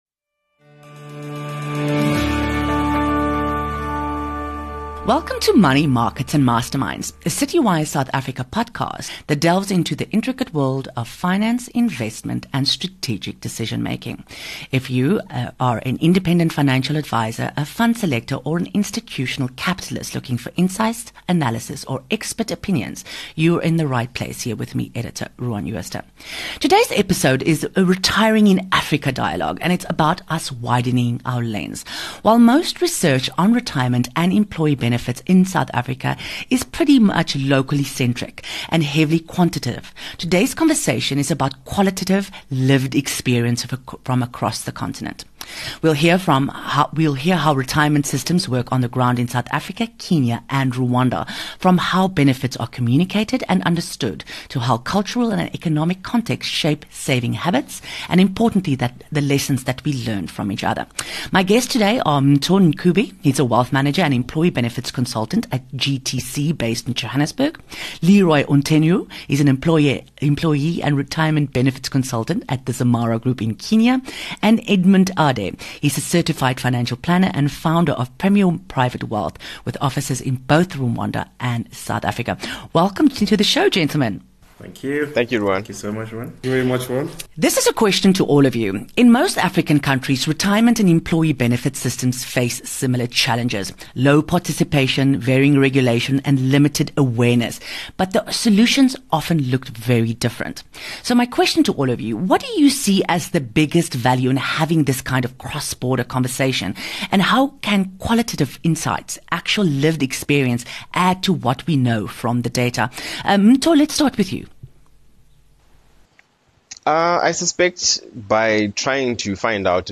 In this special Money, Markets, and Masterminds roundtable, we take retirement planning beyond South Africa’s borders.
From Rwanda’s government-matched micro-pension scheme to Kenya’s tech-driven push into the informal sector, to South Africa’s fee and access challenges, the panel unpacks shared hurdles – like low coverage and limited financial literacy – and the creative, culturally attuned solutions emerging across the continent. This is a conversation about lessons worth sharing, and how African markets can learn from each other to build more inclusive, sustainable retirement systems.